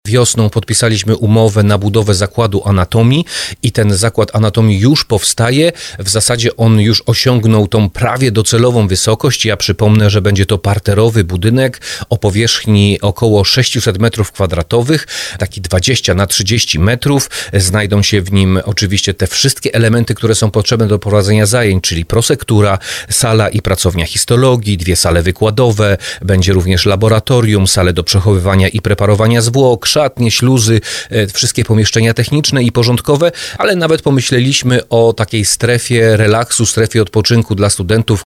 Jak mówił w rozmowie Słowo za Słowo